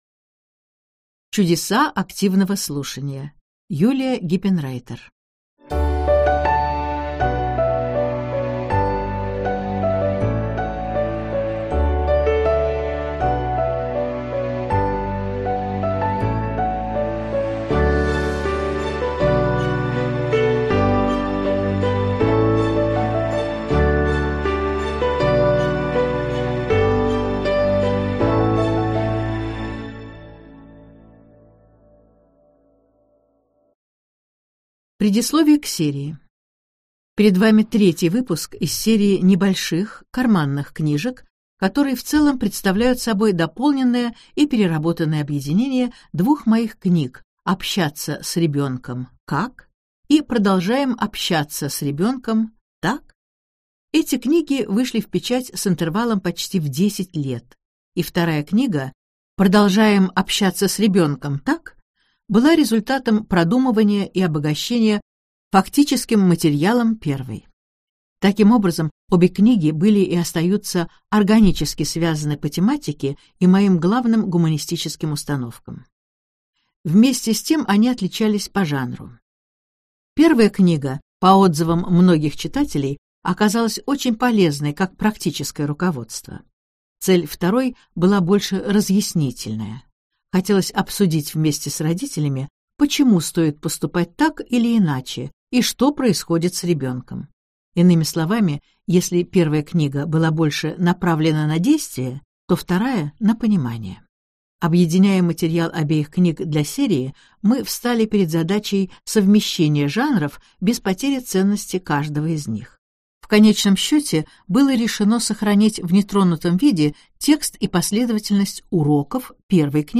Аудиокнига Чудеса активного слушания | Библиотека аудиокниг